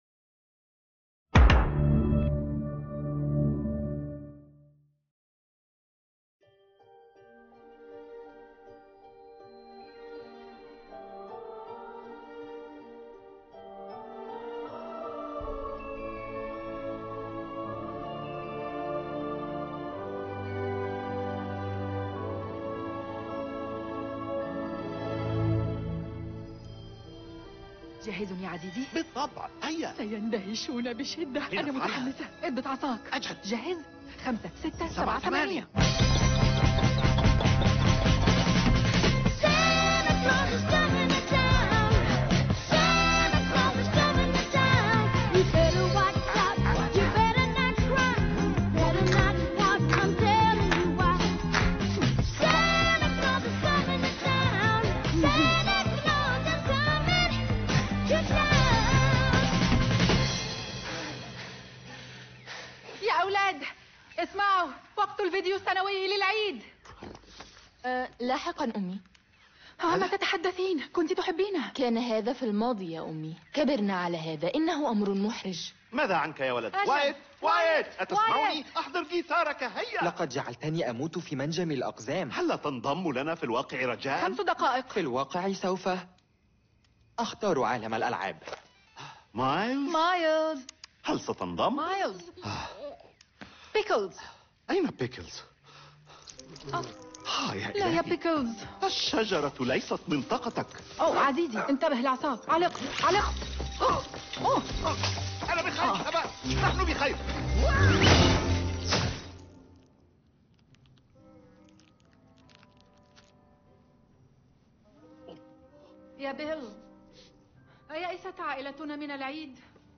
Family Switch - مدبلج للعربية 2024